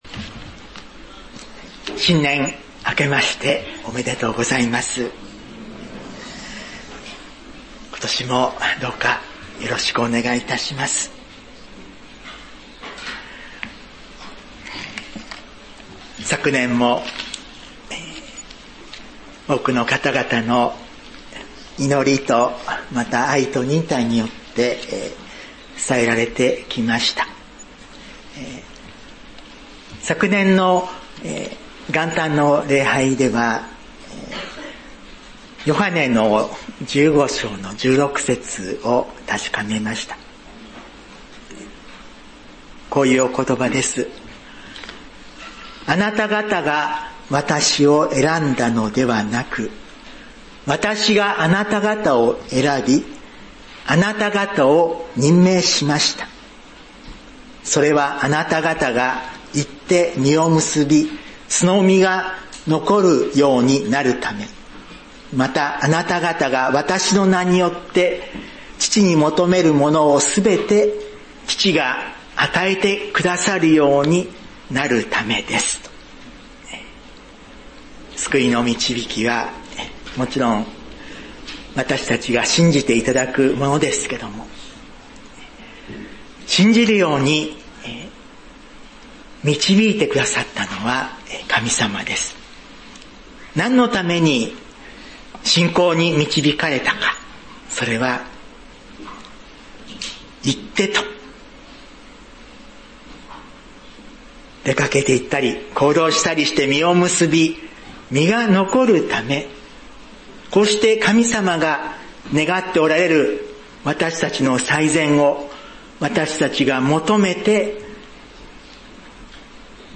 各集会のメッセージ音声／2024年